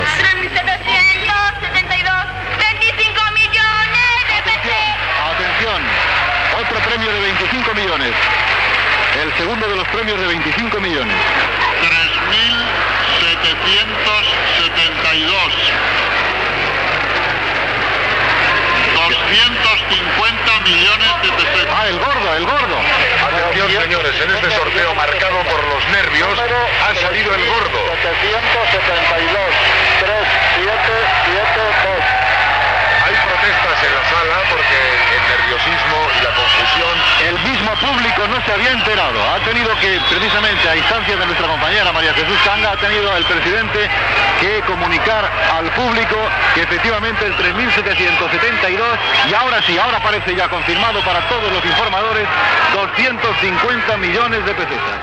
Transmissió de la Rifa de Nadal.
Info-entreteniment